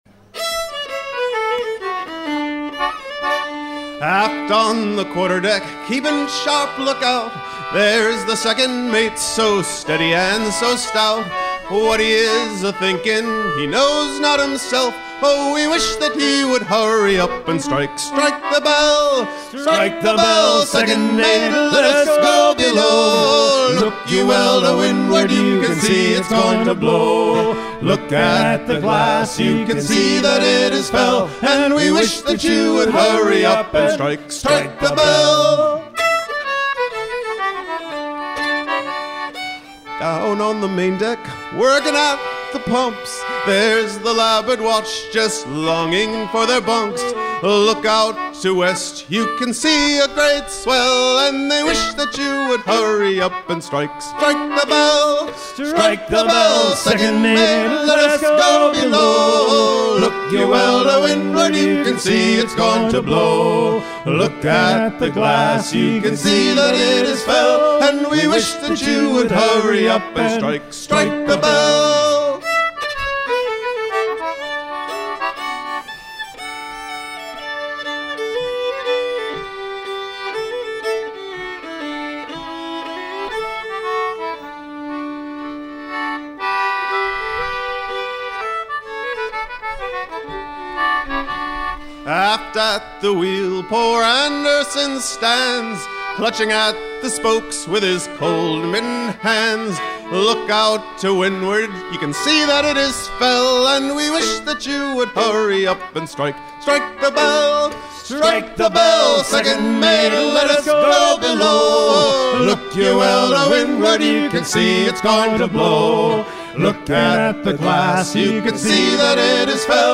gestuel : à pomper
circonstance : maritimes
Pièce musicale éditée